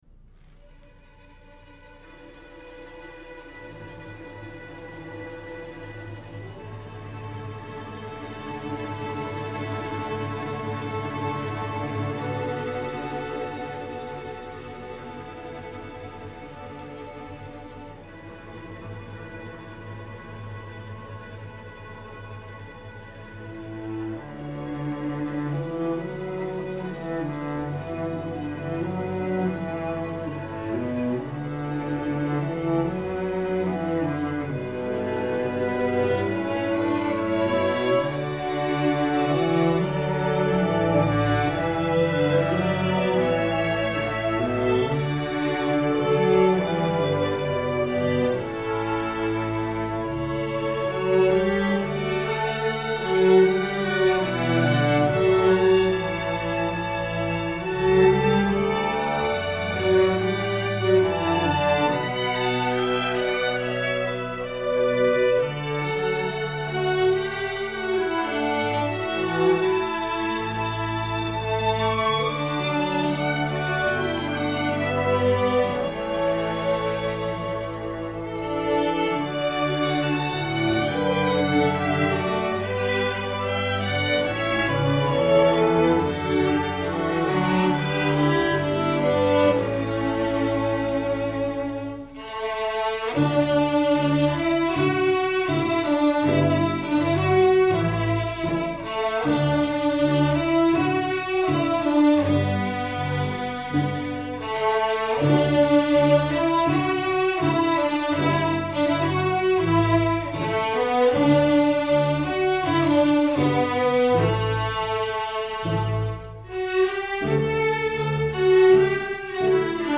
INTERMEDIATE, STRING ORCHESTRA
Notes: cello solo tremolo, pizz.,
Key: D major